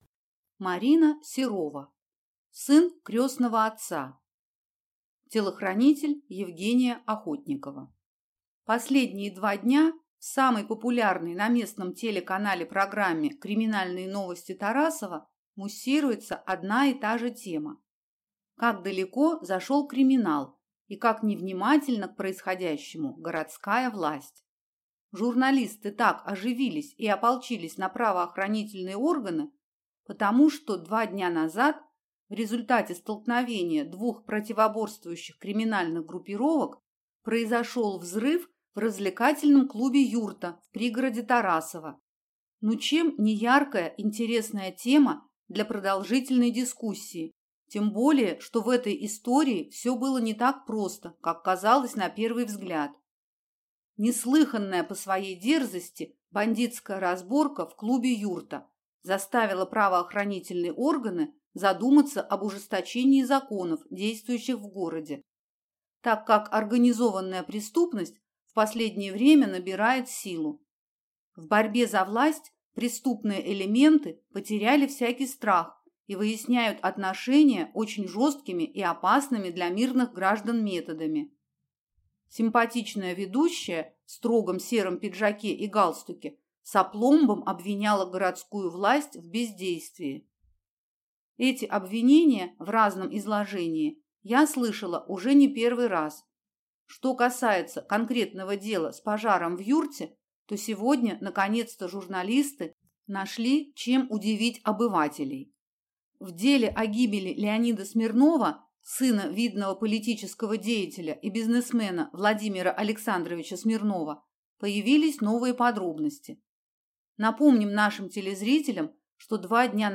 Аудиокнига Сын крестного отца | Библиотека аудиокниг